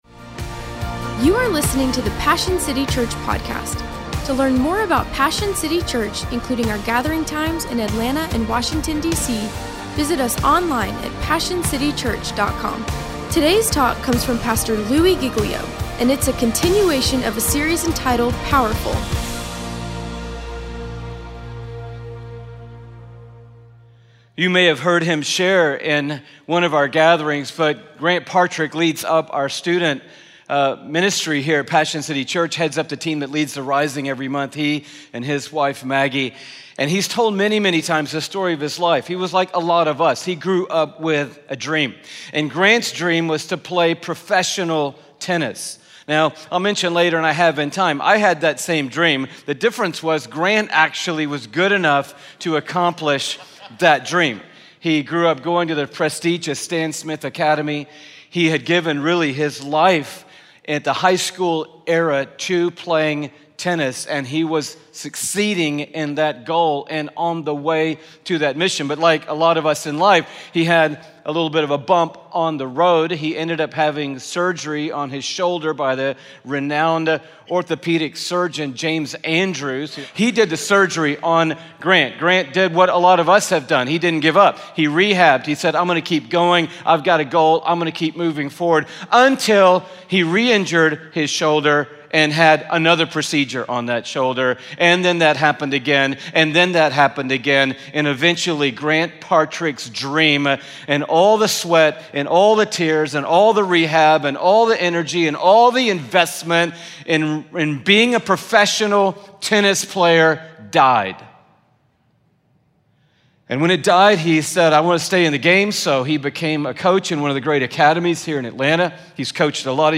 Christianity, Passion, Religion & Spirituality, Passionconferences, Messages, Louiegiglio, Sermons, Passioncitychurch, Church